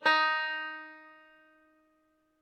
banjo
Eb4.ogg